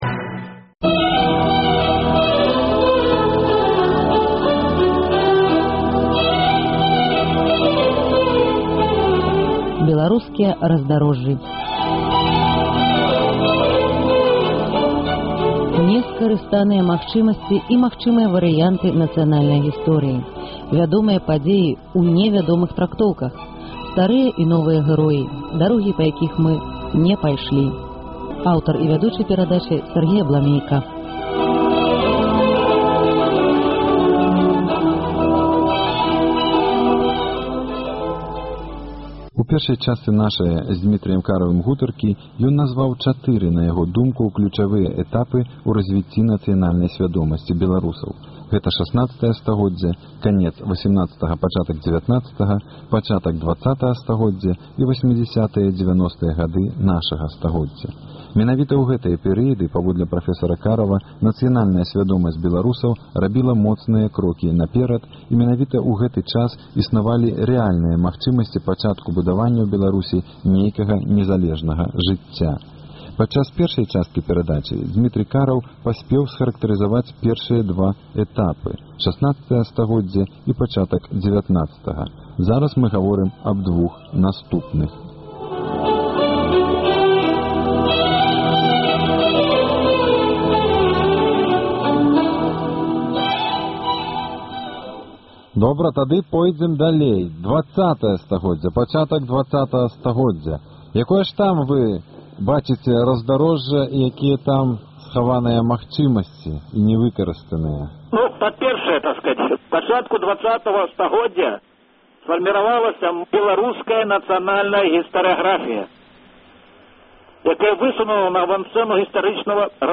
З радыёархіву Свабоды - "Беларускія раздарожжы".
Размова пра этапы разьвіцьця нацыянальнай сьвядомасьці беларусаў.